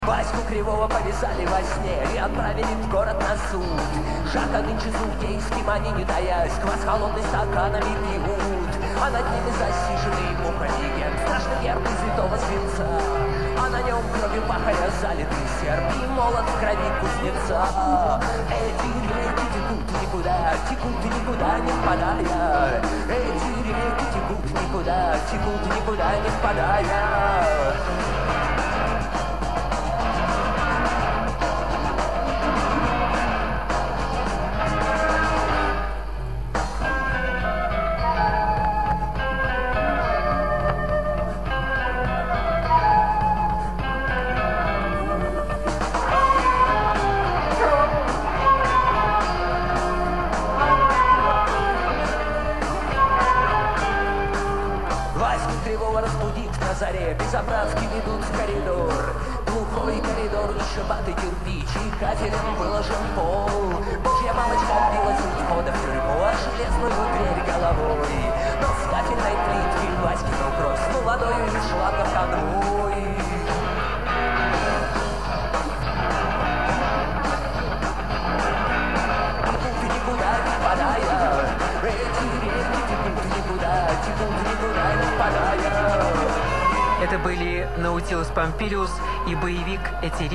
с фестиваля